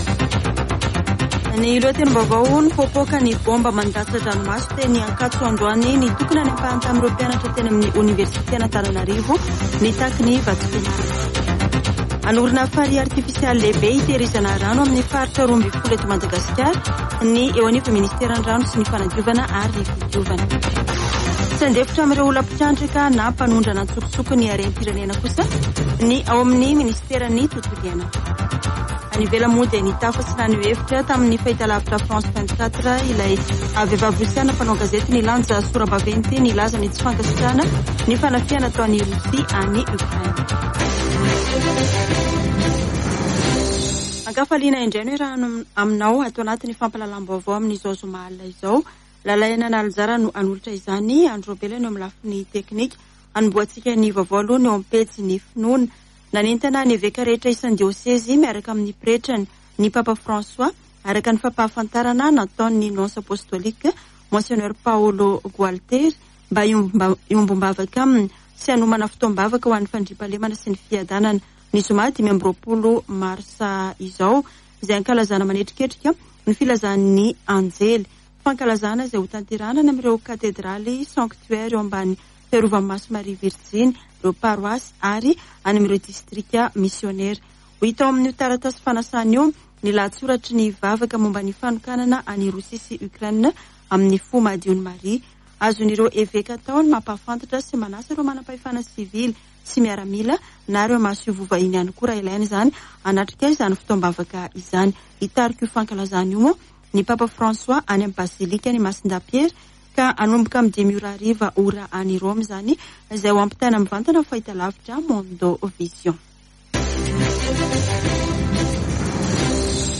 [Vaovao hariva] Zoma 18 marsa 2022